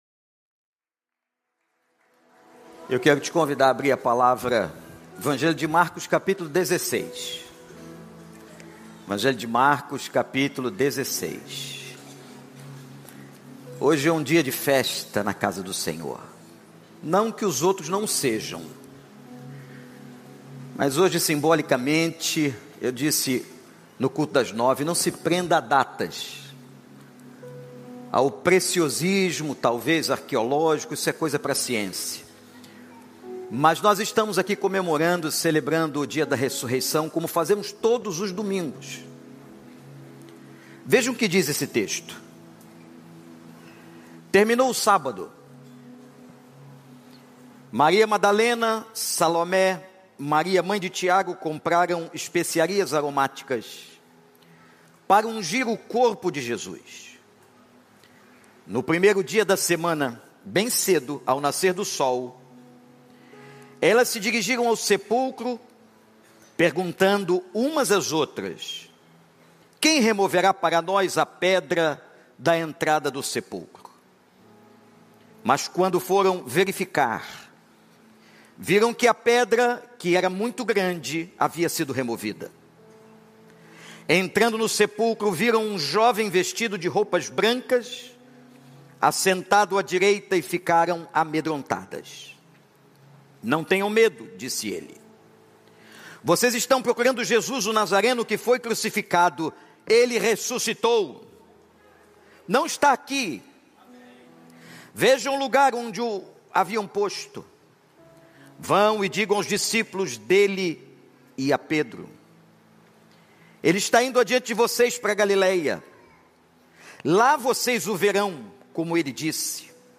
Especial de Páscoa